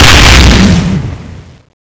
white_explode.wav